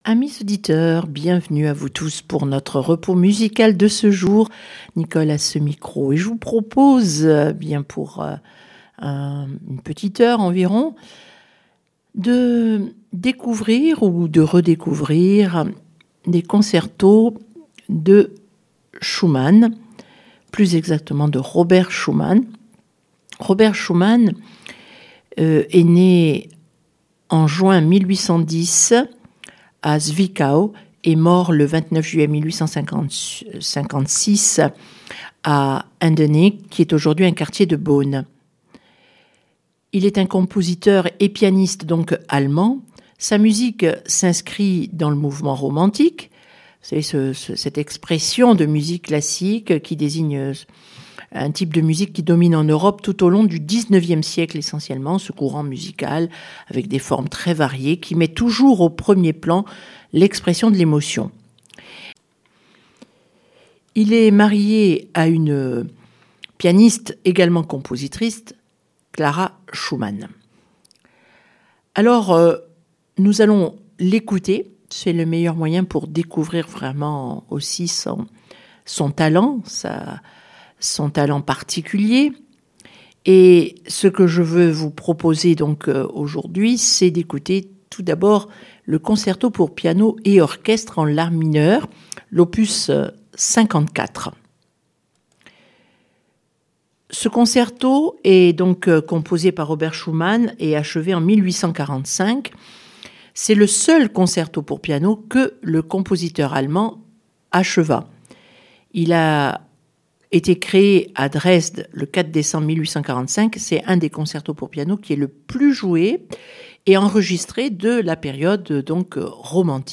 schumman concerto piano